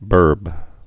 (bûrb)